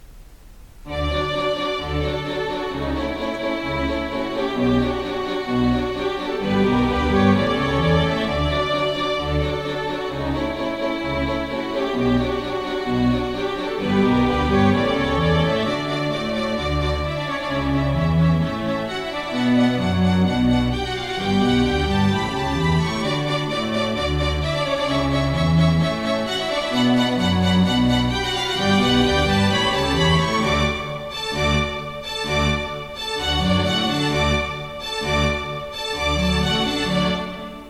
PCの近くにマイクロホンを置いて録音しました。